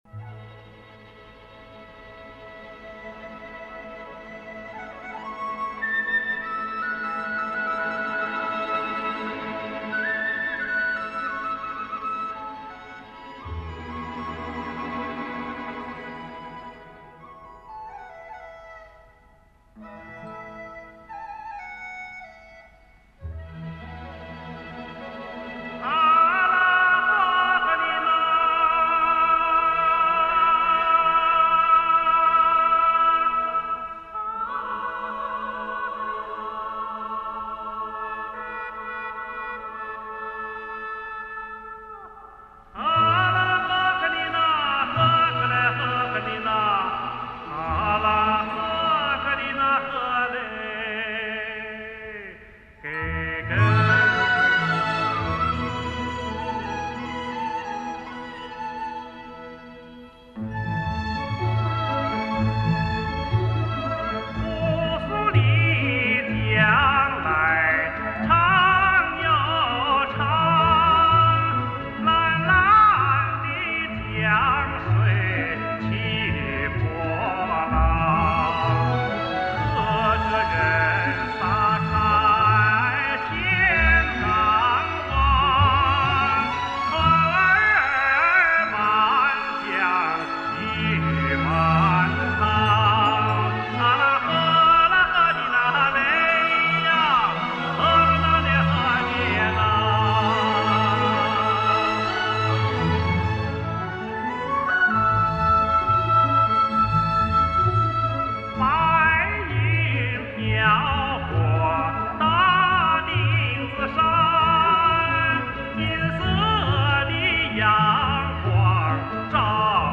赫哲族民歌
历史录音